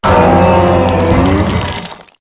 tinglu_ambient.ogg